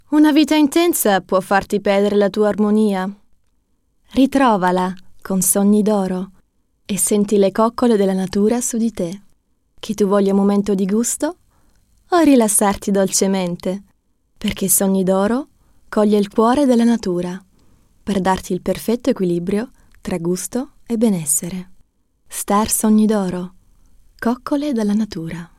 Meine Stimme – klar, warm, wandelbar.
In meinem eigenen Studio entstehen hochwertige Aufnahmen – effizient, professionell und mit viel Herz für das gesprochene Wort.
Werbung –ITALIENISCH – (sanft) 🇮🇹
Werbung-ITALIENISCH-sanft.mp3